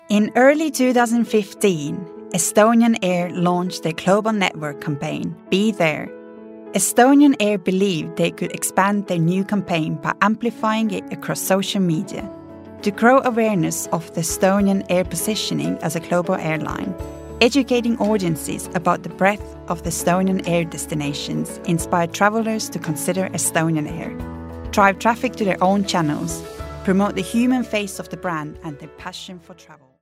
Estonian, Female, 20s-30s